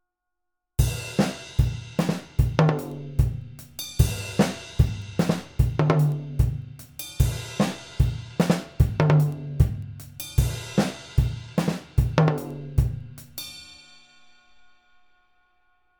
Now we add the toms on the third beat, let’s try a double hit